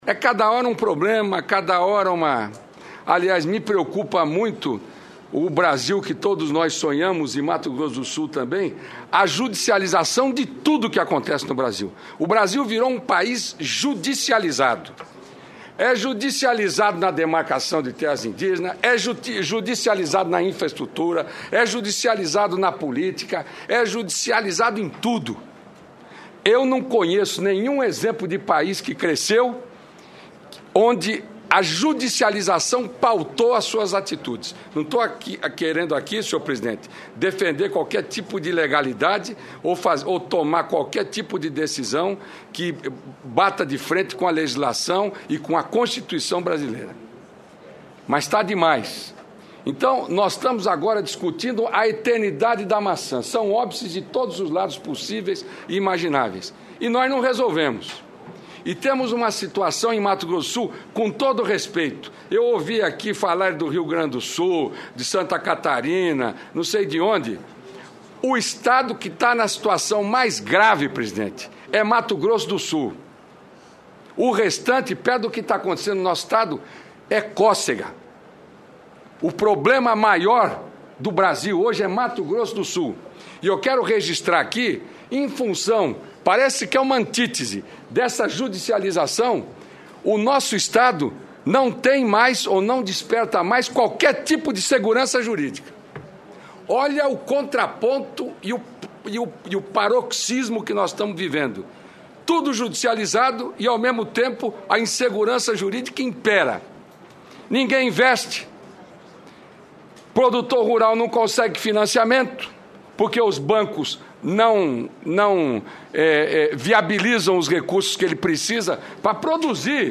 Confira a íntegra dos principais debates da Comissão de Agricultura e Reforma Agrária do Senado